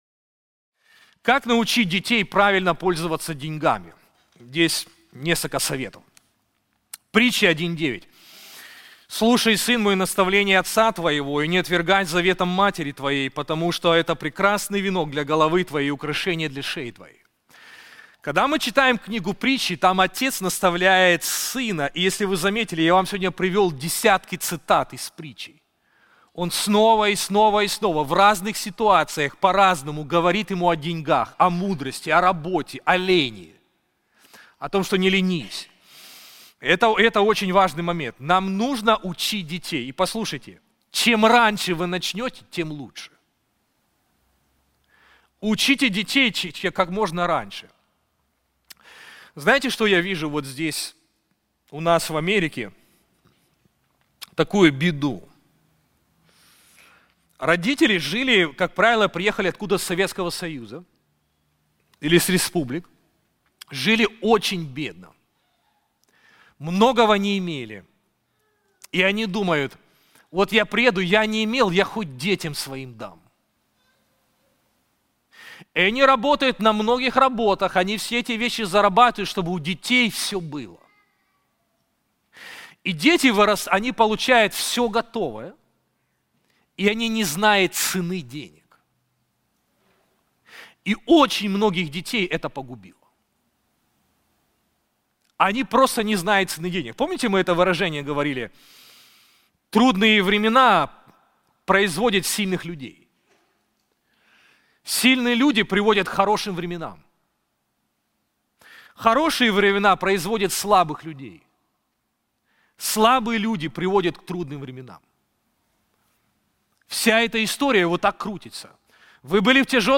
Мы подготовили специальный семинар о библейских принципах управления финансами.